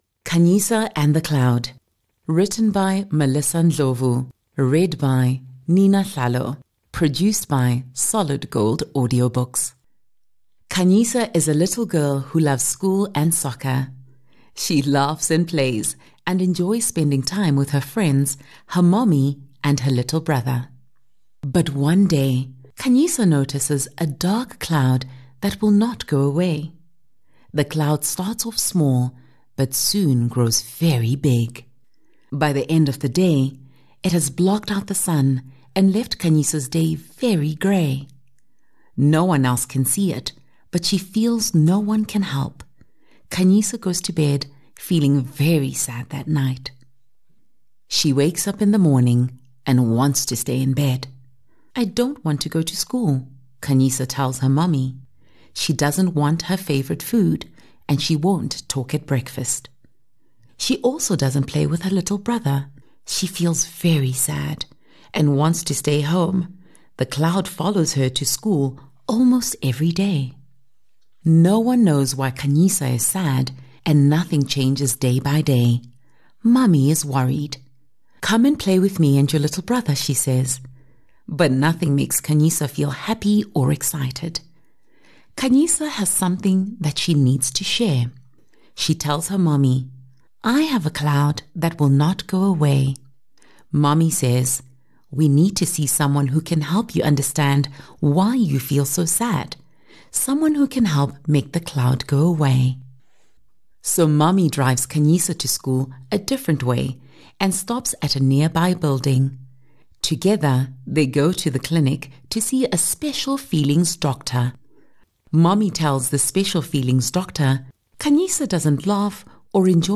Audiobook Samples from Solid Gold